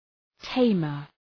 {‘teımər}